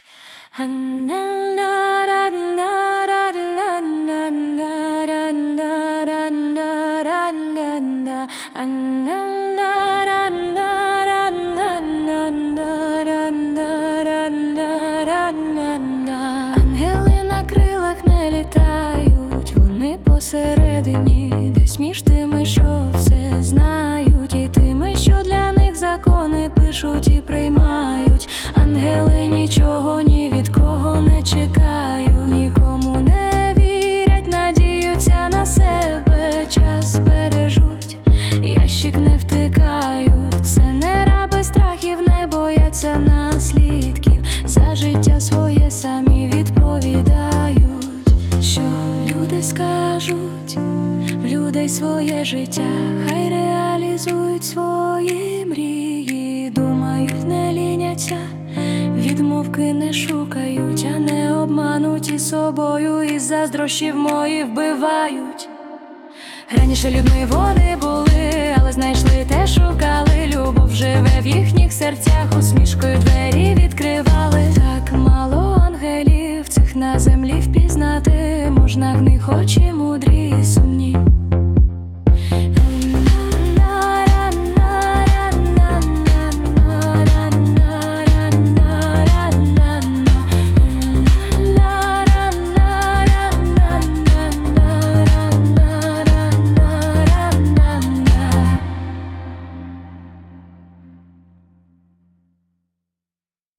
Ангели (+🎧музична версія)